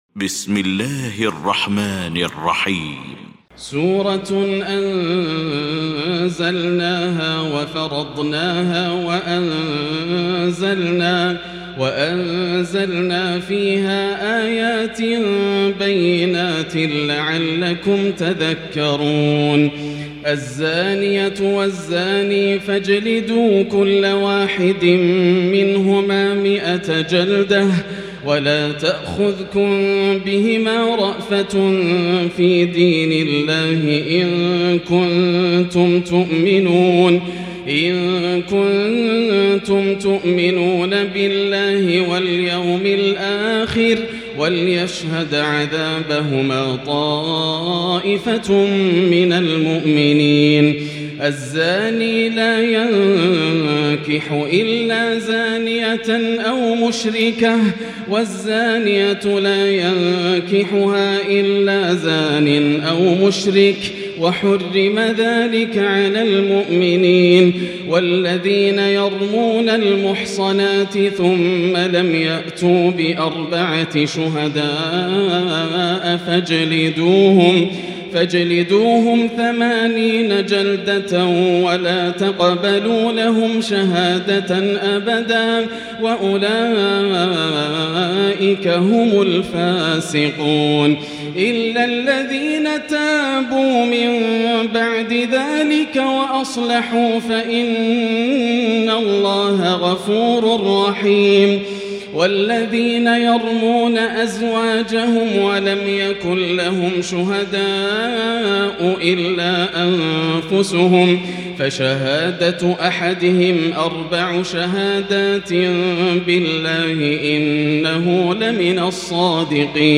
المكان: المسجد الحرام الشيخ: فضيلة الشيخ ياسر الدوسري فضيلة الشيخ ياسر الدوسري النور